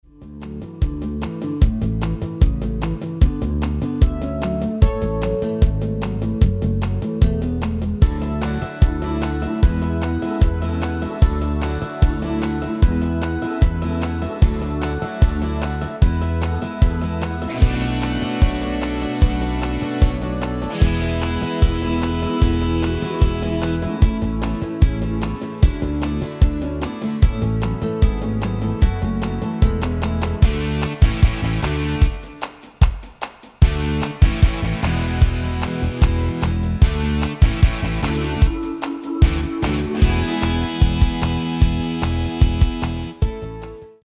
Demo/Koop midifile
Genre: Pop & Rock Internationaal
- GM = General Midi level 1
- Géén vocal harmony tracks